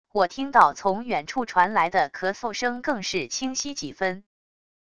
我听到从远处传来的咳嗽声更是清晰几分wav音频生成系统WAV Audio Player